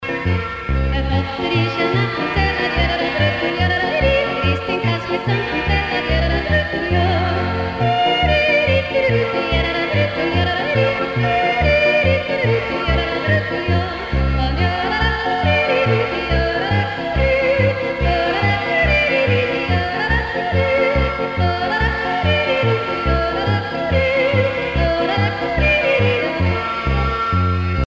danse : polka
Pièce musicale éditée